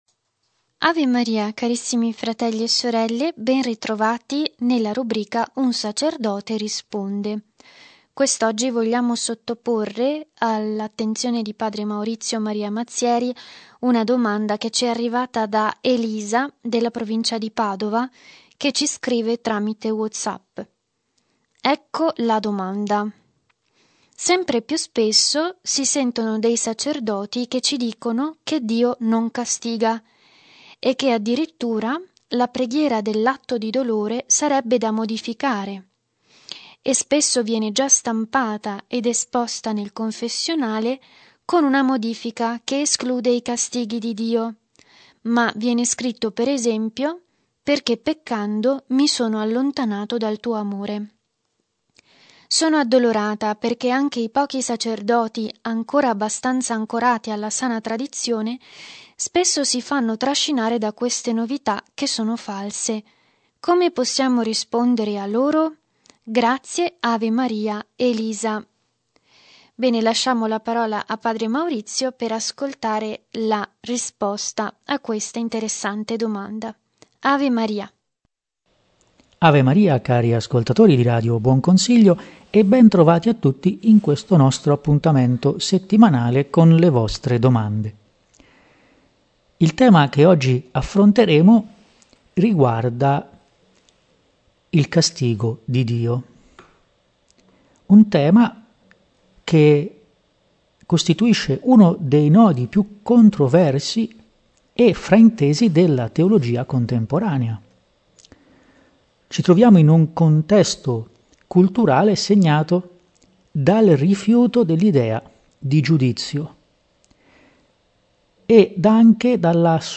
Genere: Un sacerdote risponde.